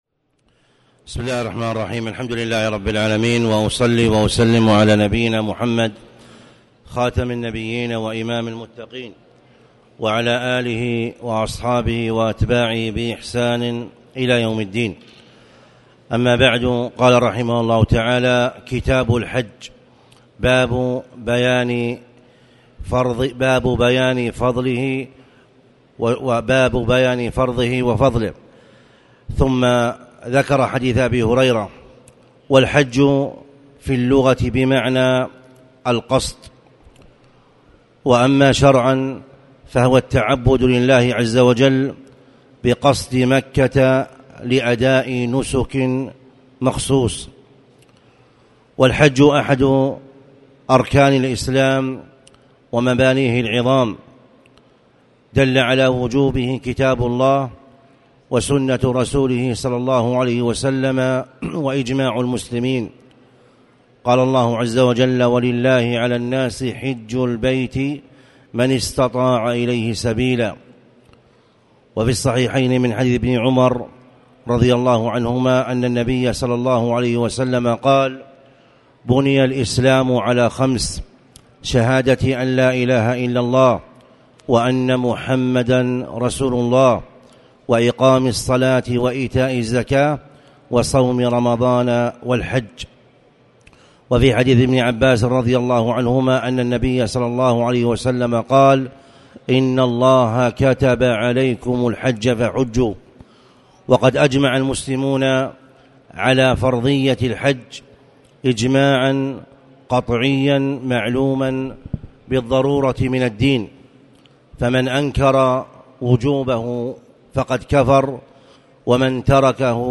تاريخ النشر ٢ ذو القعدة ١٤٣٨ هـ المكان: المسجد الحرام الشيخ